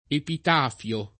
epitaffio [epit#ffLo] s. m.; pl. -fi — lett., alla latina e alla greca, epitafio [